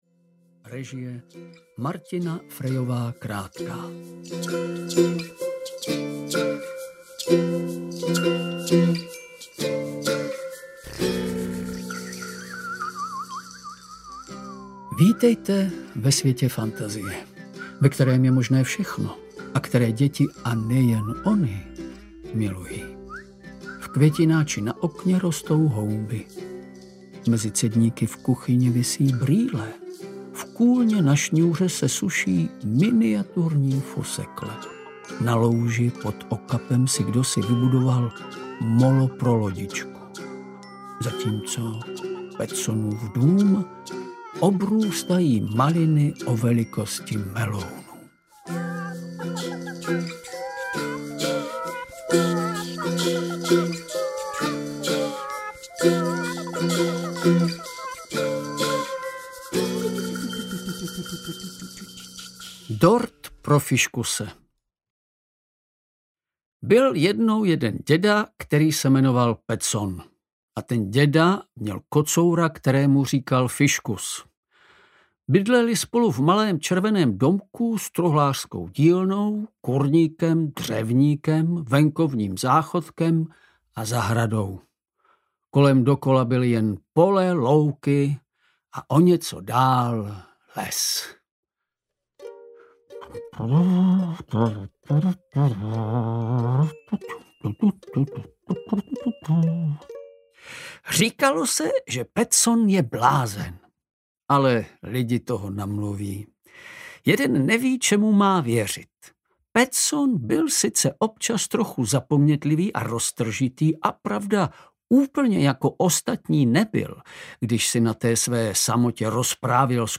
Dobrodružství kocoura Fiškuse a dědy Pettsona 1 audiokniha
Ukázka z knihy
• InterpretVladimír Javorský
dobrodruzstvi-kocoura-fiskuse-a-dedy-pettsona-1-audiokniha